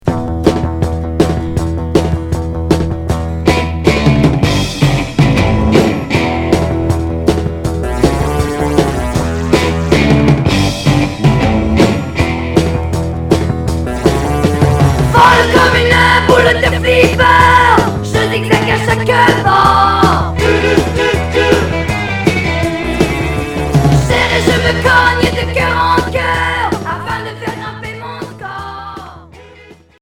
Glam